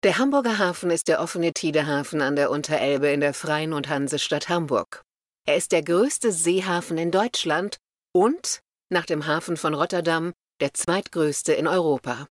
dunkel, sonor, souverän, sehr variabel
Mittel minus (25-45)
Kölsch
Tale (Erzählung)